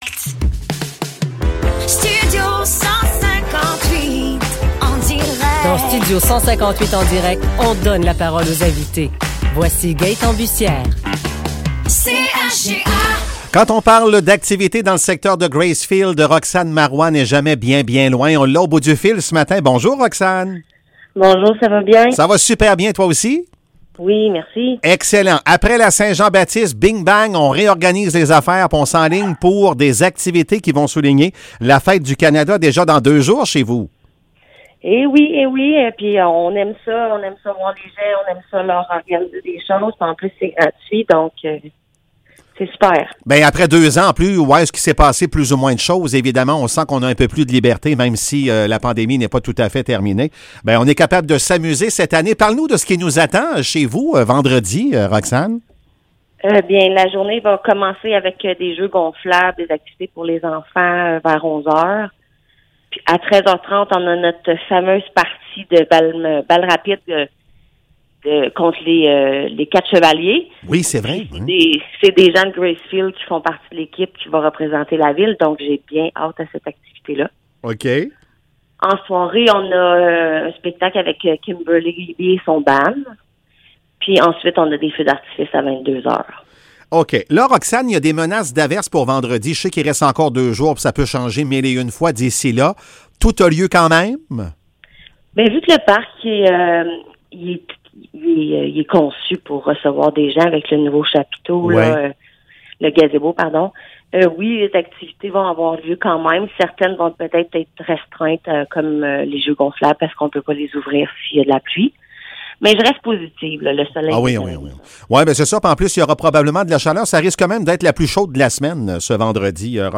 Entrevues